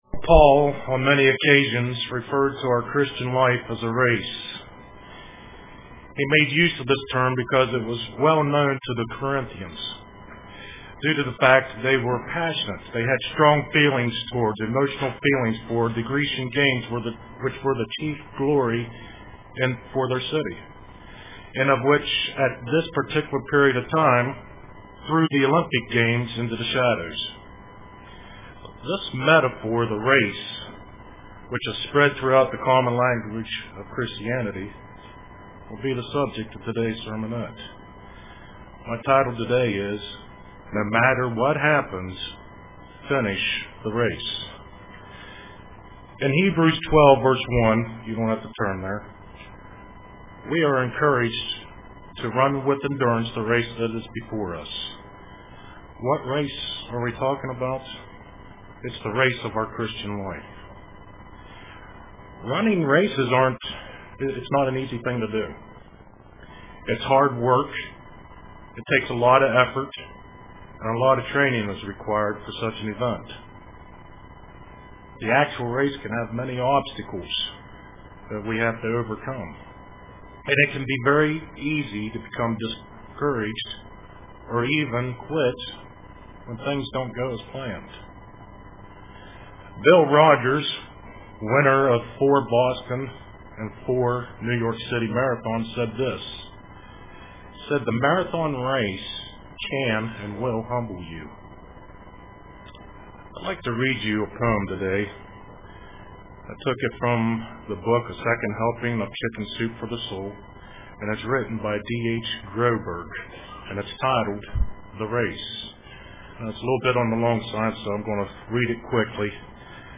Print No Matter What-Finish the Race UCG Sermon Studying the bible?